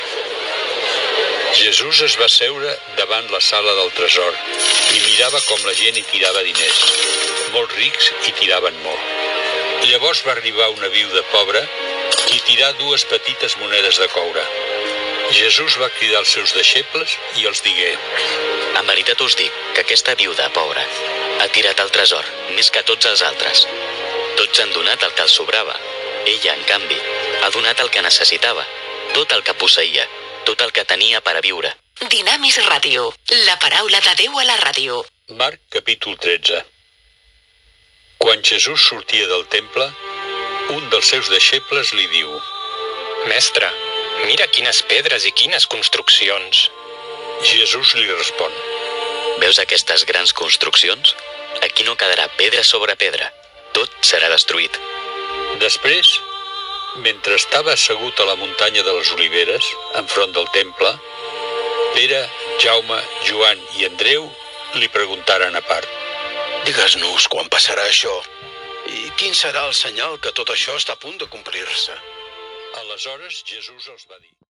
Fragment dramatitzat de l'Evangeli de Marc:Jesús assegut davant la sala del tresor mira com la gent hi tirava diners. Indicatiu de l'emissora. Nou fragment de l'Evangeli de Marc: Jesús anuncia la destrucció del temple
FM